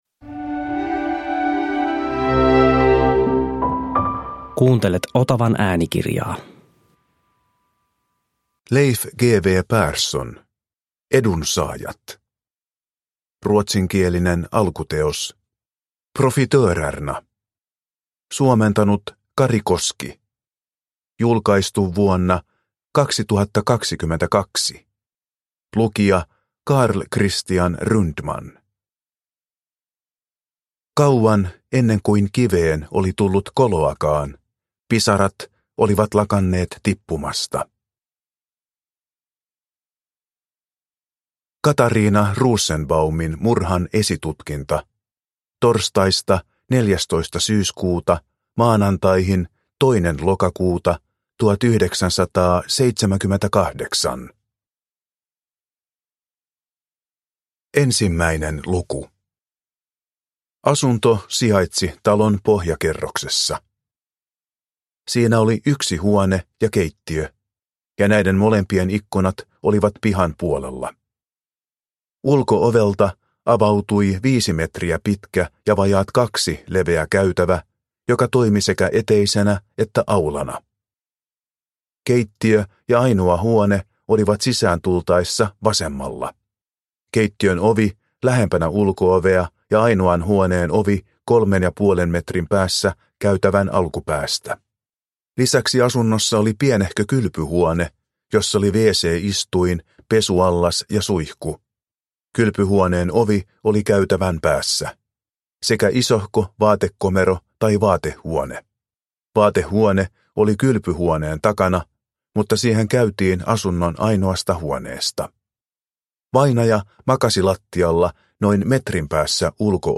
Edunsaajat – Ljudbok – Laddas ner